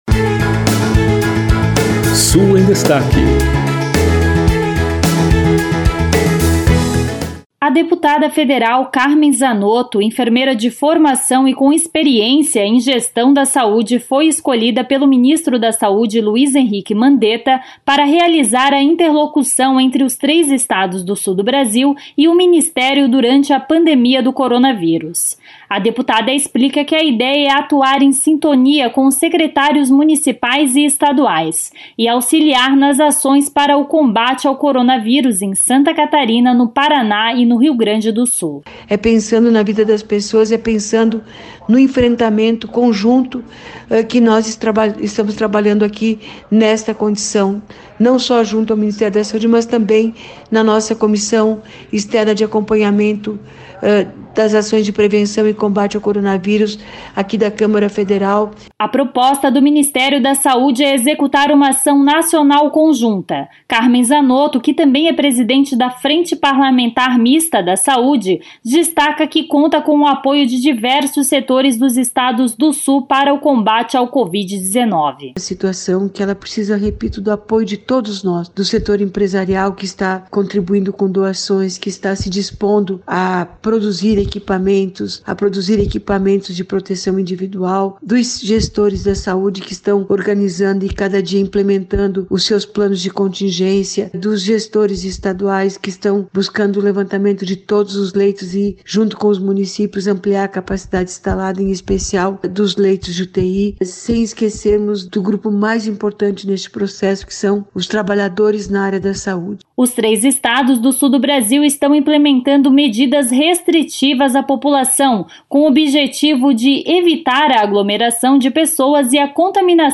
De Florianópolis, repórter